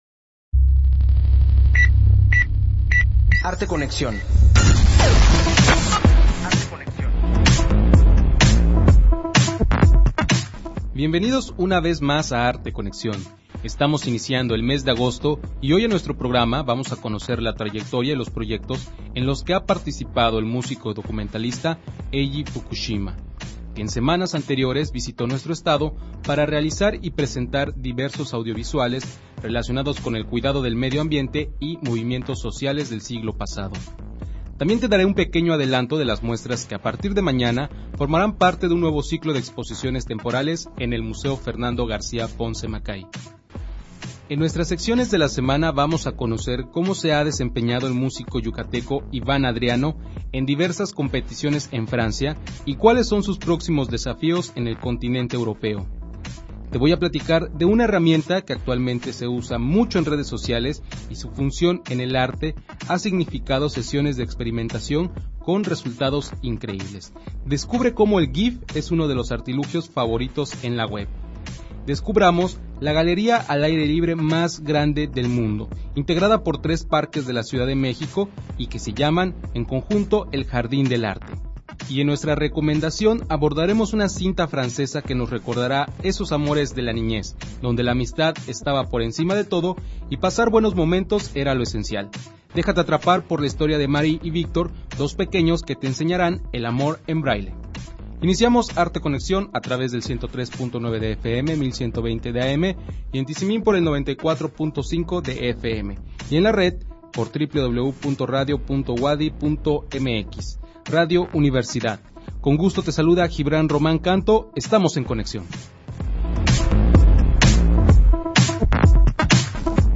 Emisión de Arte Conexión transmitida el 3 de agosto del 2017.